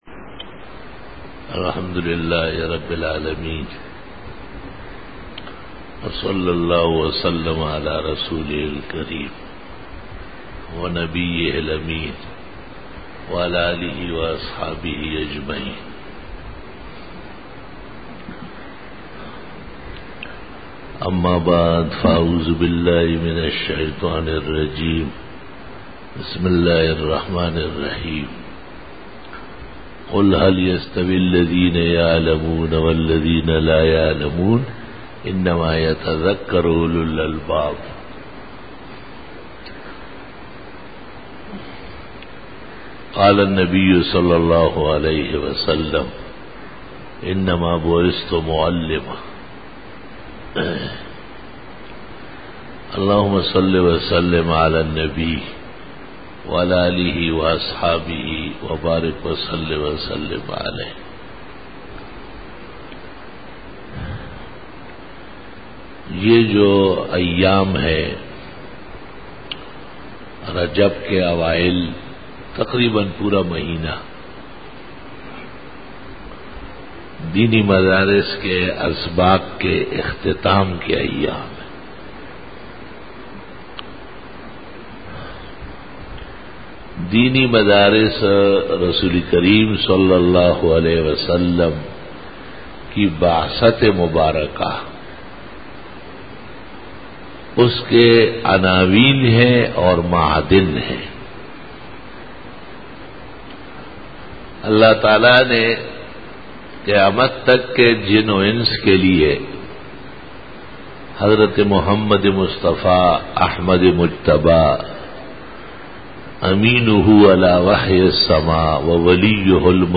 Khitab-e-Jummah 2013